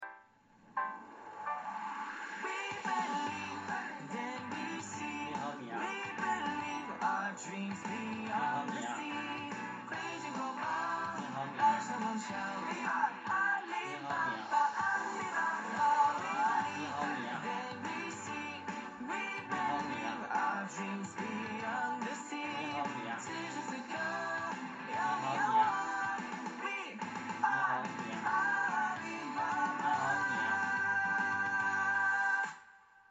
体验界面在页面右侧，可以在“测试内容”栏内看到我们预先准备的一段唤醒音频，点击播放按钮可以试听，音频中音乐声较大，唤醒词“你好米雅”声音较小，前三次大约在第5秒、第8秒、第11秒左右，需要仔细分辨才能听得出。
从强音乐背景噪音中获取唤醒词